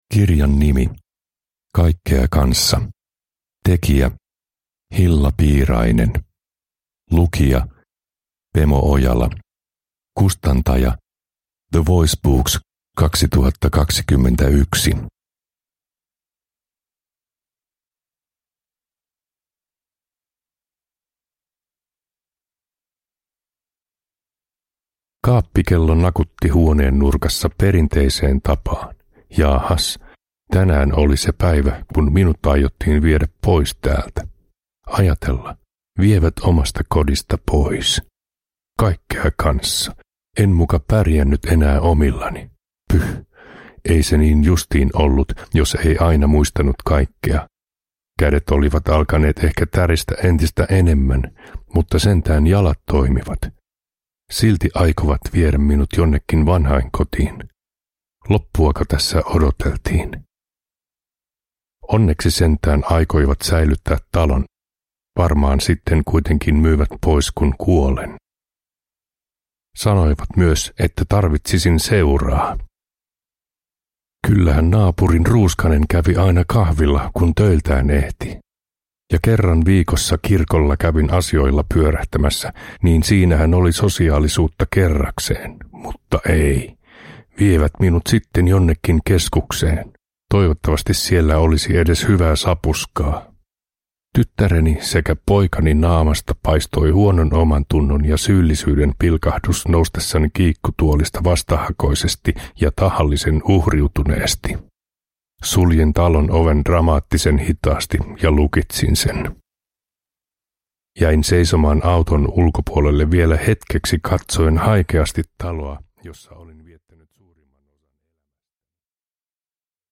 Kaikkea Kanssa! – Ljudbok – Laddas ner